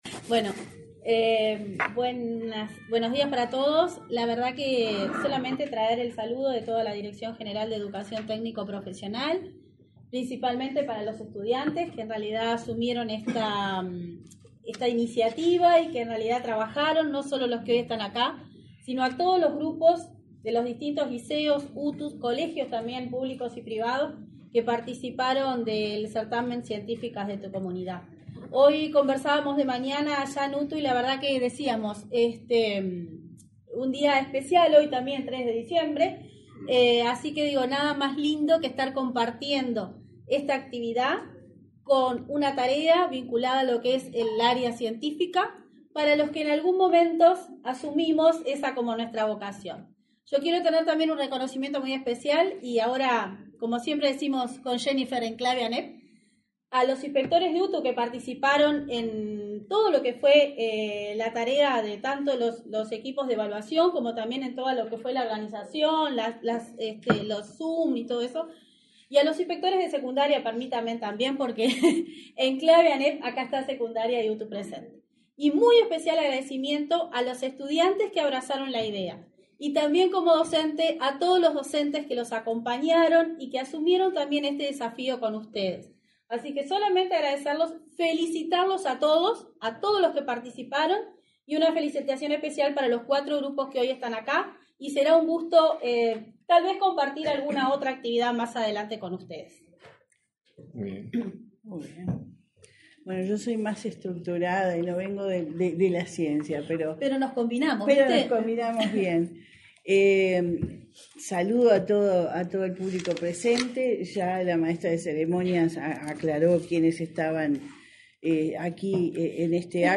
Palabras de autoridades en entrega de premios Científicos de tu Comunidad
Este martes 3 en Montevideo, la subdirectora y la directora de Educación Secundaria, Laura Otamendi y Jenifer Cherro respectivamente; el director